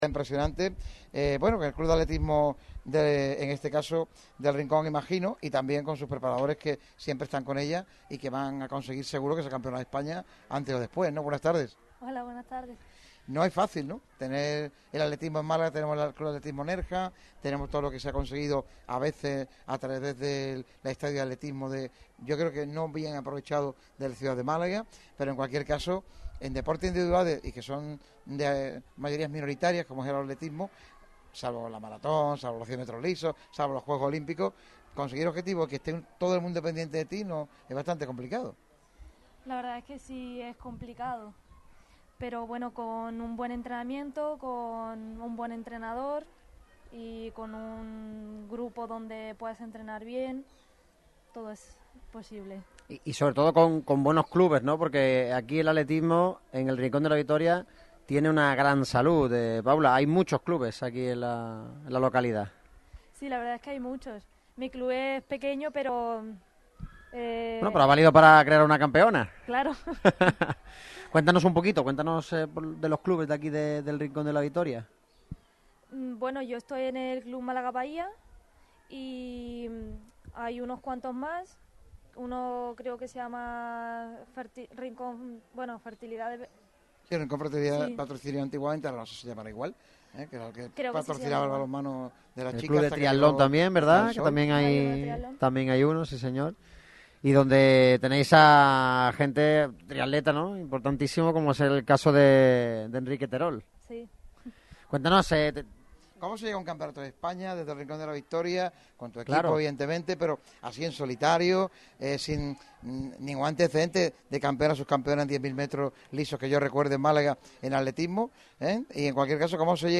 Un programa celebrado en el CEIP Nuestra Señora de La Candelaria con la colaboración principal del Rincón de la Victoria.